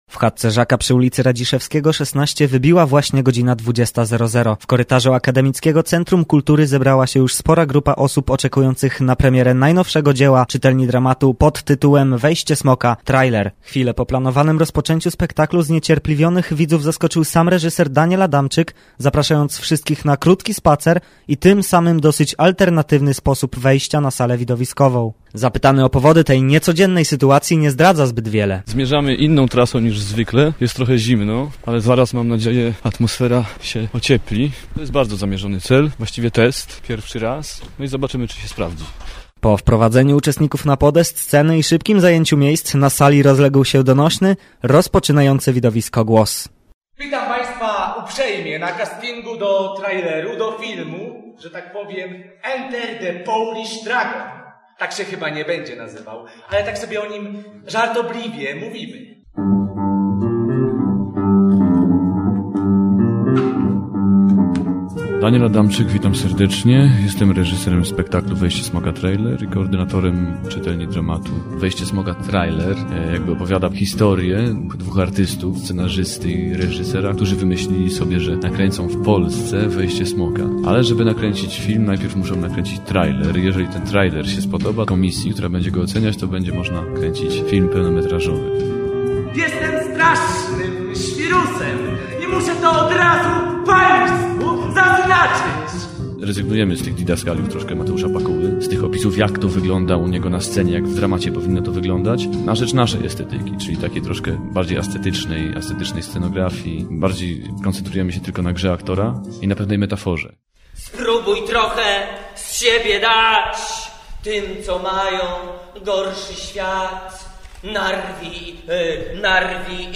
Posłuchajcie relacji z próby generalnej pierwszego pełnowymiarowego spektaklu Czytelni Dramatu „Wejście smoka. Trailer”